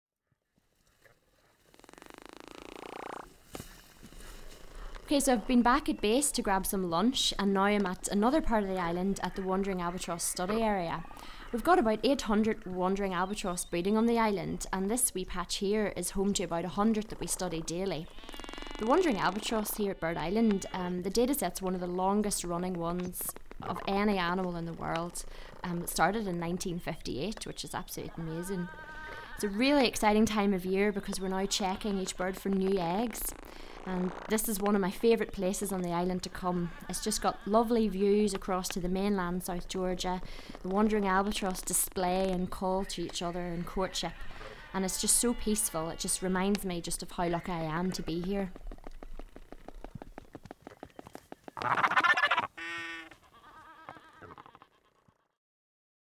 4. Wandering albatross checks
4.-Wandering-albatross-checks.mp3